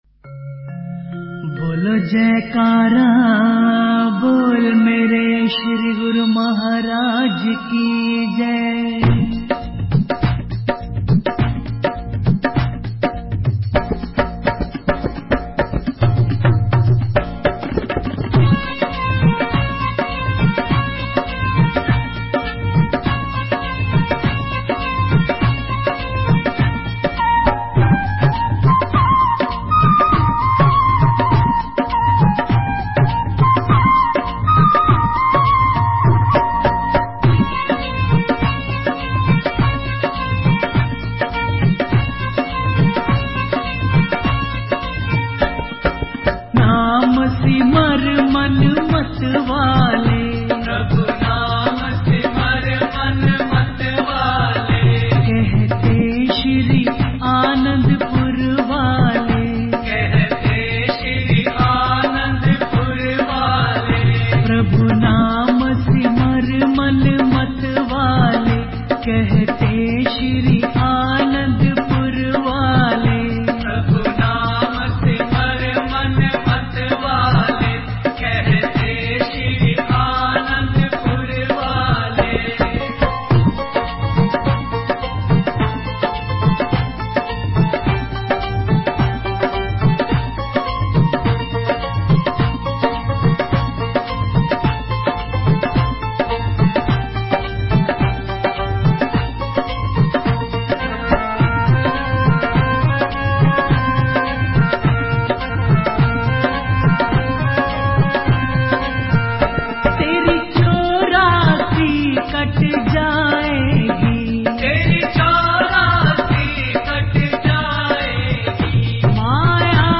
Bhajan 5 - Naam Simar Maan